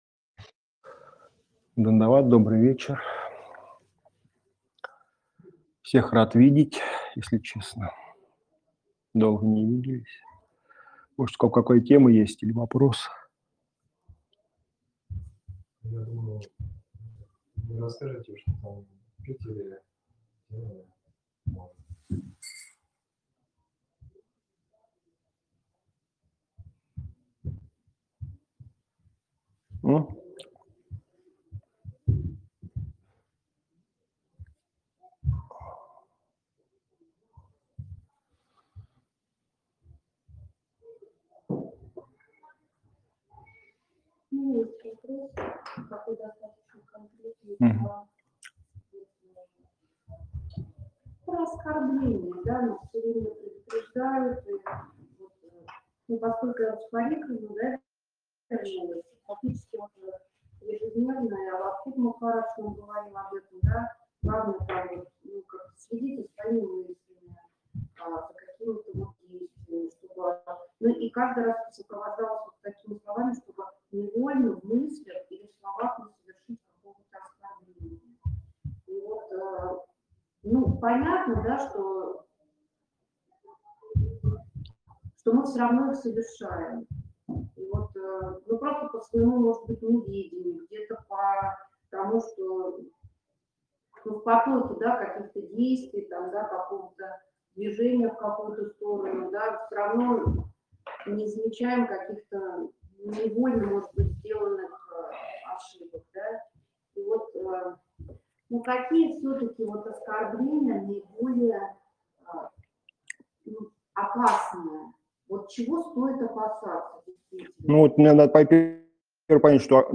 Место: Кисельный (Москва)
Лекции полностью